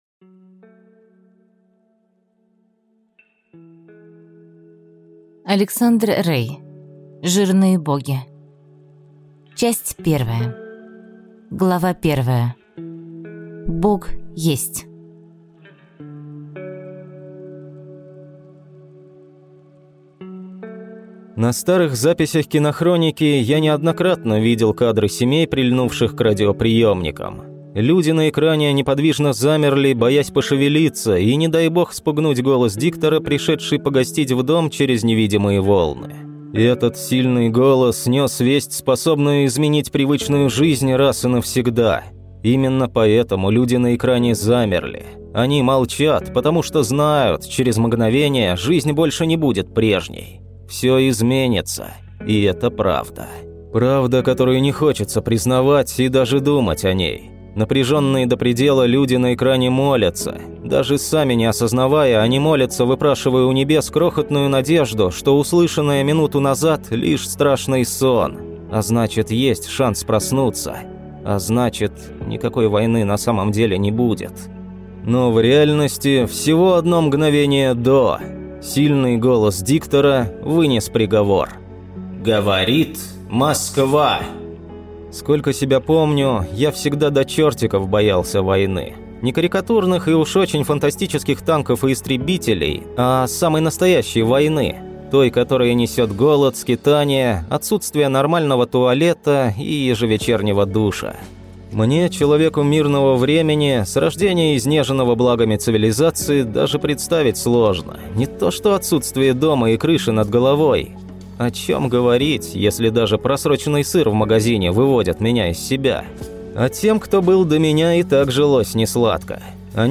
Aудиокнига Жирные Боги